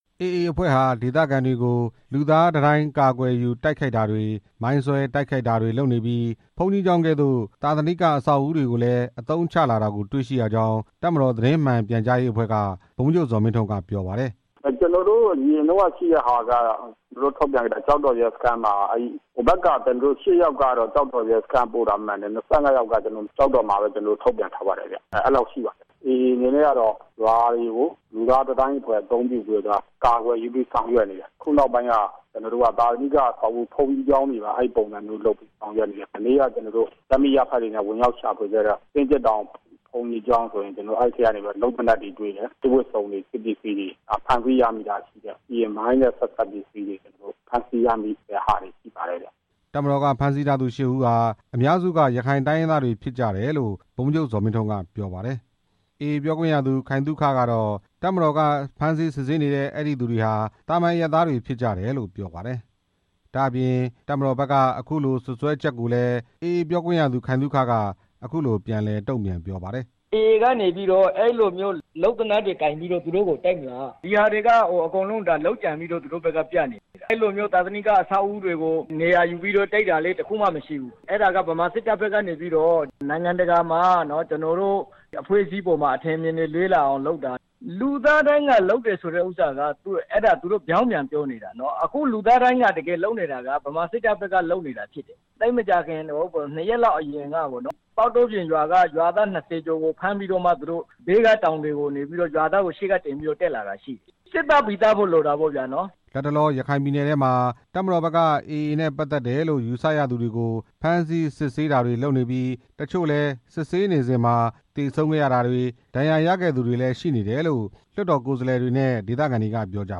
မြောက်ဦးမြို့နယ် ပြည်နယ်လွှတ်တော် ကိုယ်စားလှယ် ဦးထွန်းသာစိန် ပြောသွားတာပါ။